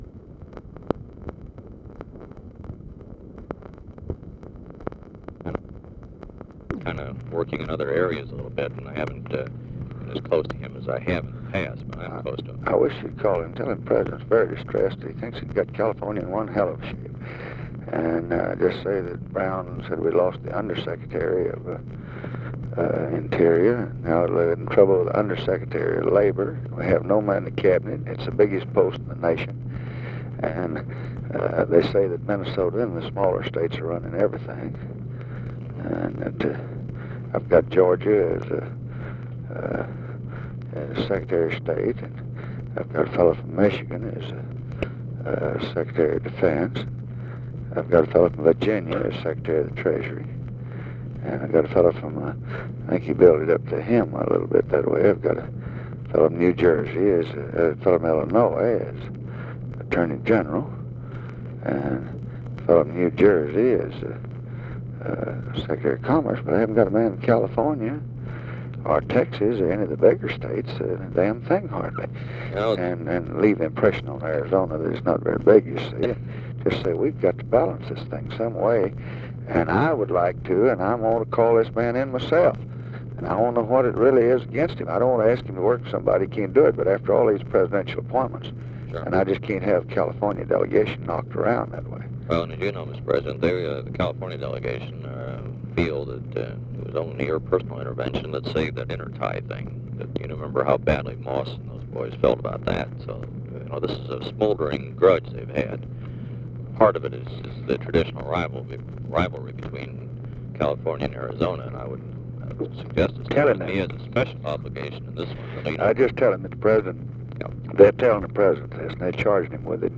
Conversation with LEE WHITE, March 26, 1965
Secret White House Tapes